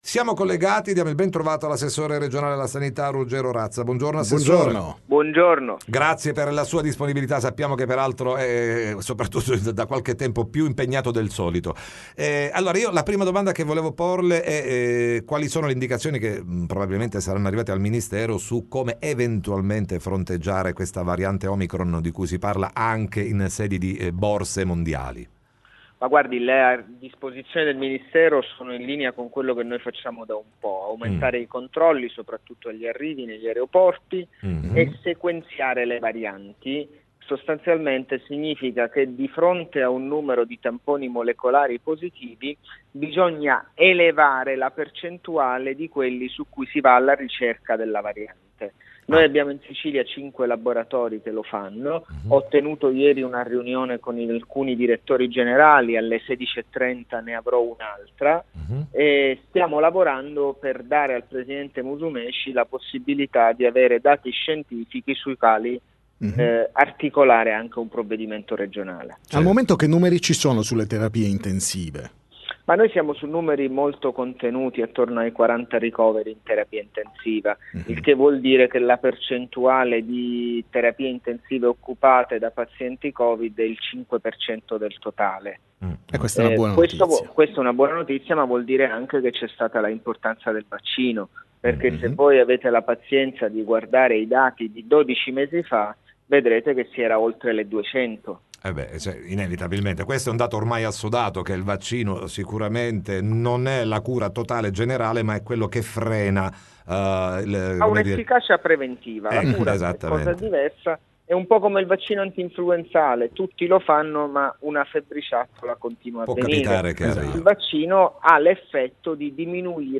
Time Magazine intervista l’assessore Ruggero Razza